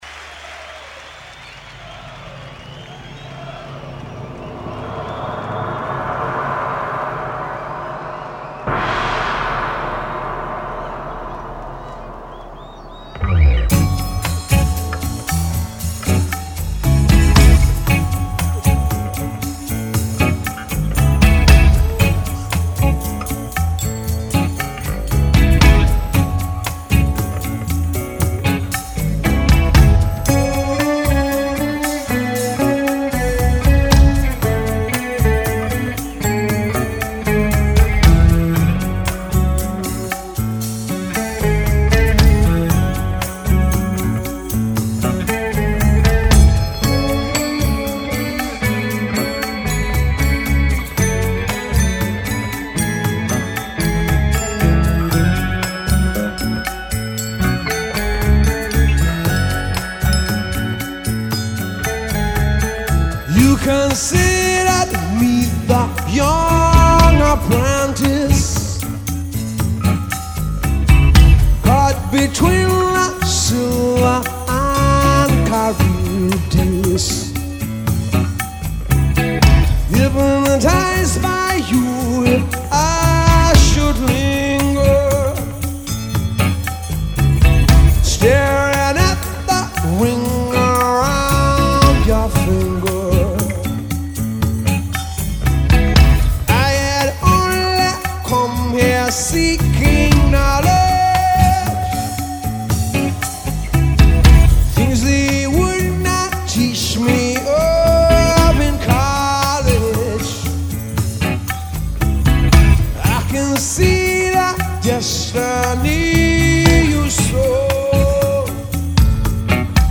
in front of a huge crowd